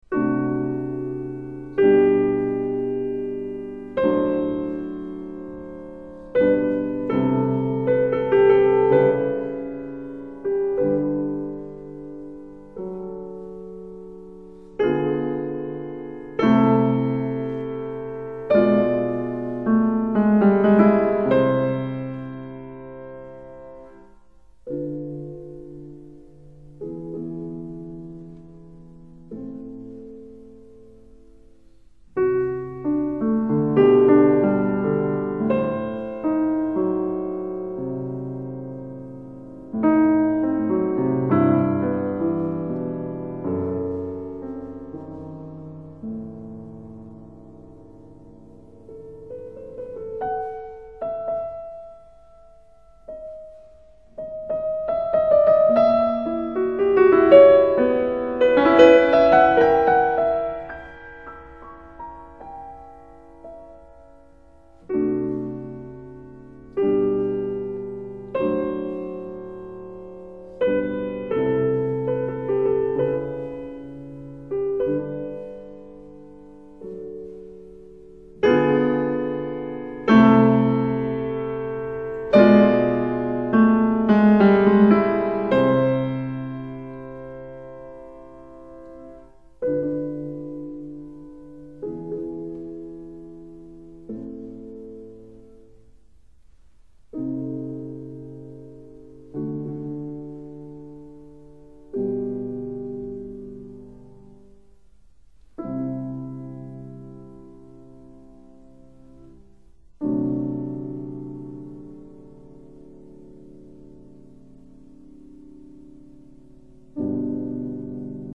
作品手法洗炼，形象刻画生动准确，心理描写逼真，欢快动人，饶有情趣，但也流露出一种因为童年逝去而产生的惆怅感。